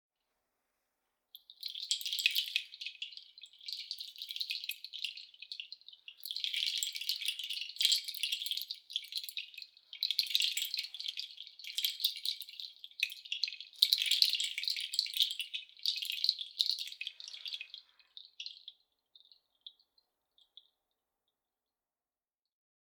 Meinl Sonic Energy Flower of Life Kenari Chimes Medium - 8 Schnüre (SKFOLM)
Neben ihrem beruhigenden Klang sind sie auch ein toller Dekorationsartikel.